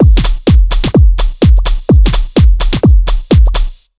127BEAT6 8-L.wav